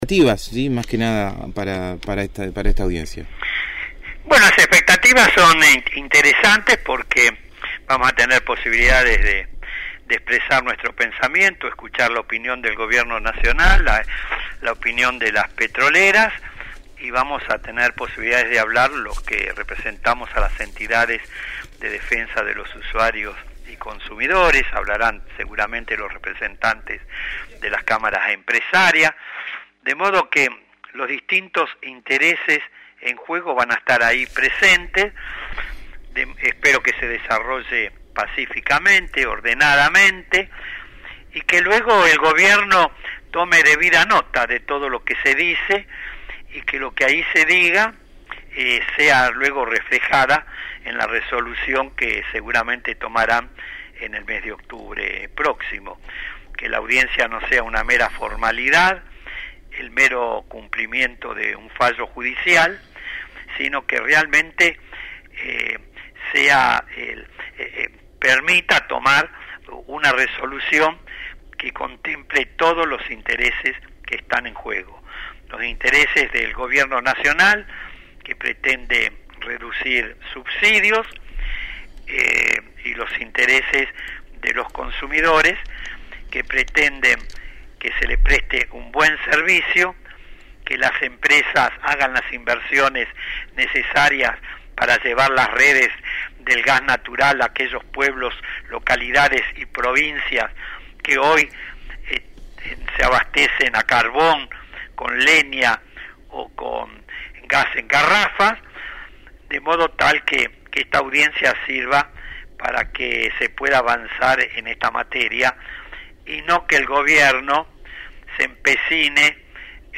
El doctor Héctor Polino, representante de «Consumidores libres», dialogó con el equipo de «El Hormiguero» sobre la audiencia pública que comenzó ayer, viernes 16 de septiembre, para abordar los aumentos en la tarifa de gas y que se desarrollará durante tres días, en los que expondrán 375 oradores.